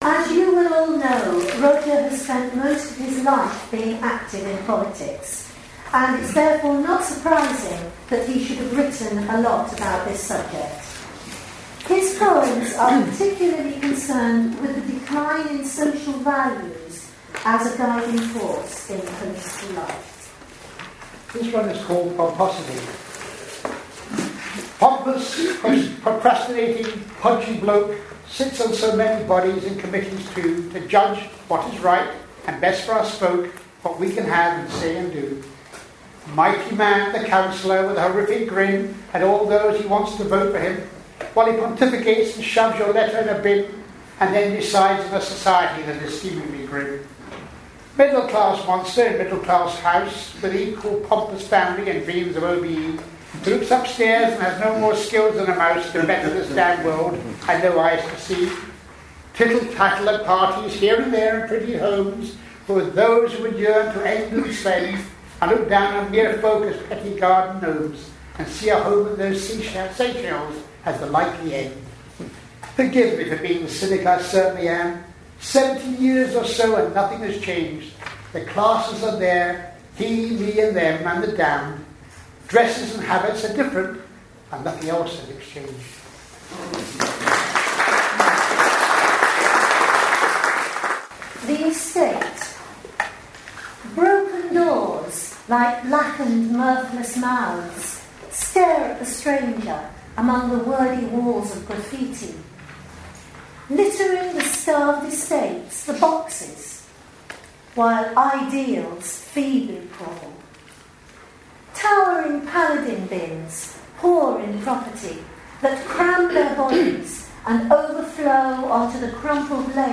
Sounds Like then then put on a launch concert at the Working Men's College on 19 February 2011.